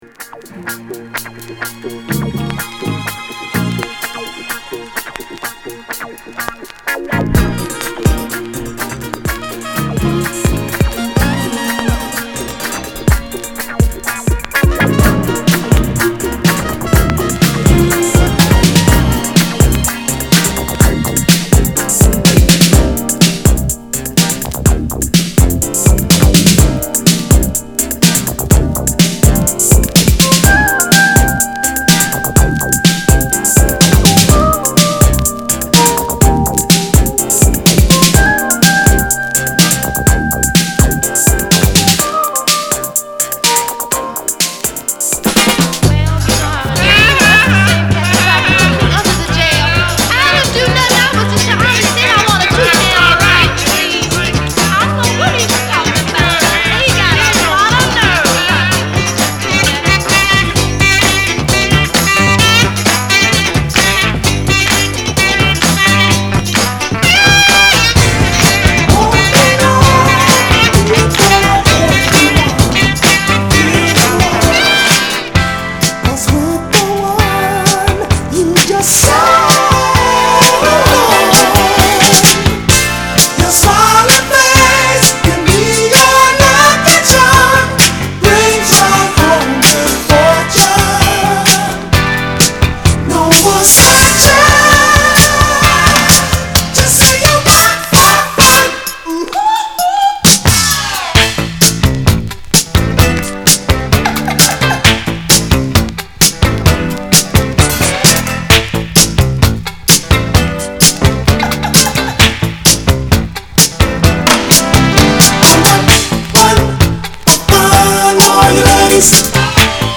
category Disco